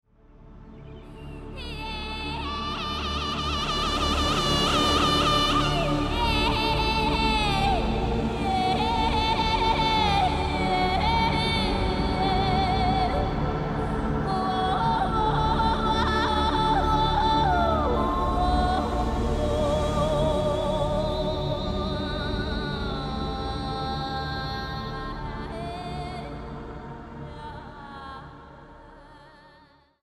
- Recorded and mixed at AVAF Studios, Zurich, Switzerland